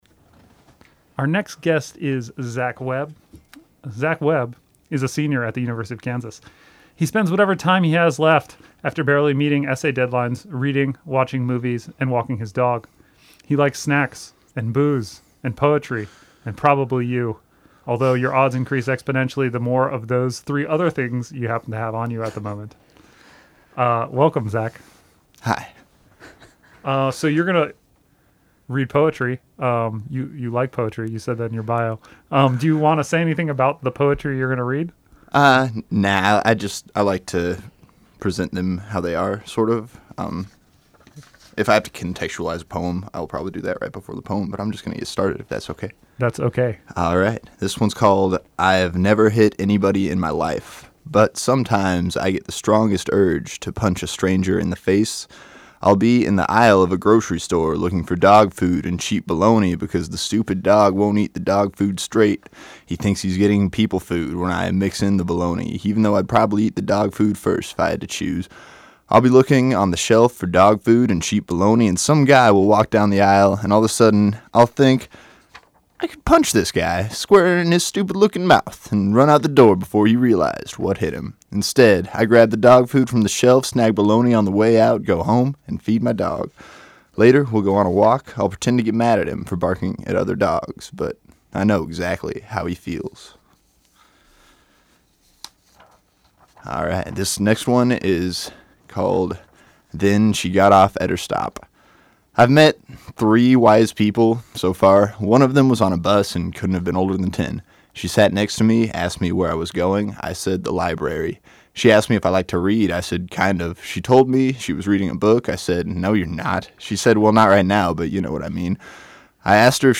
came in the studio to read us a number of his poems– most of which were absolutely hilarious.